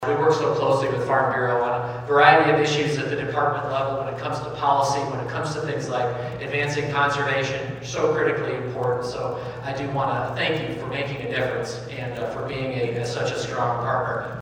Iowa Secretary of Agriculture was in the listening area earlier this week and served as the keynote speaker for the Carroll County Farm Bureau’s annual meeting and member appreciation dinner.